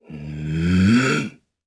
Chase-Vox_Casting2_jp.wav